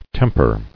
[tem·per]